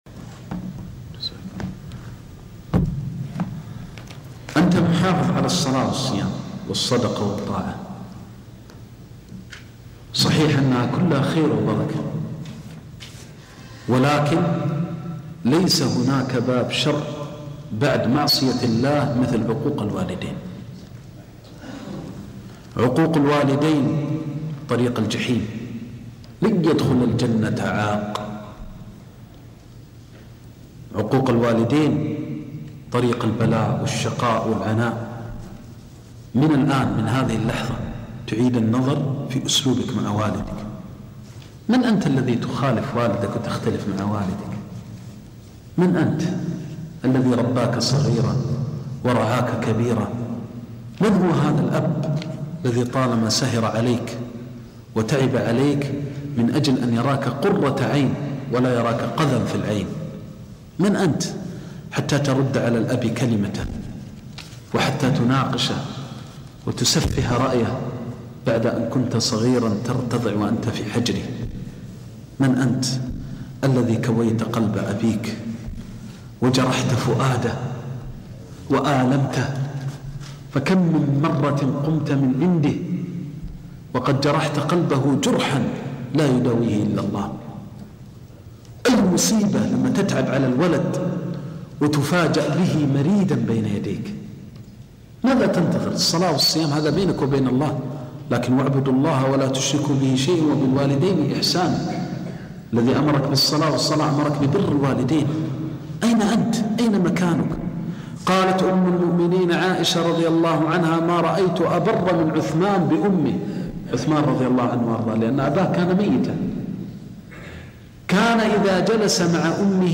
قصص ومواعظ